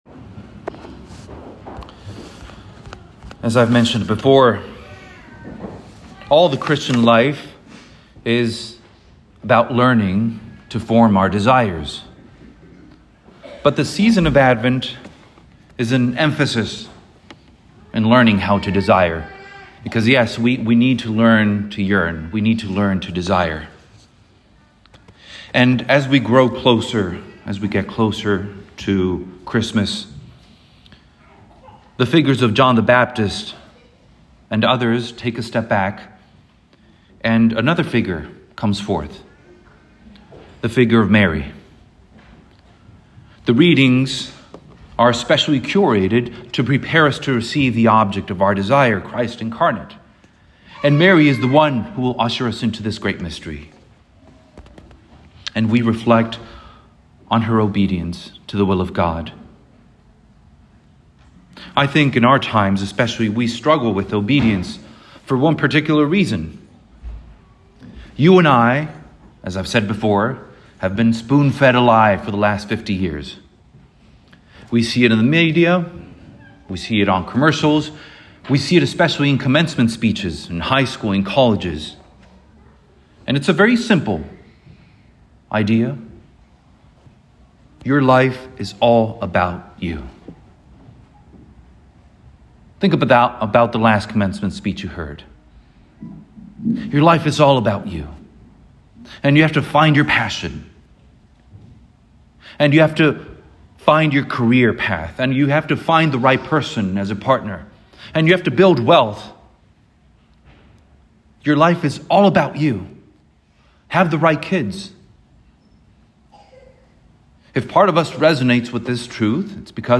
musings of a parish priest in dialogue with the world and the gospel: curated homilies, reflections on our faith and on what gives life to life.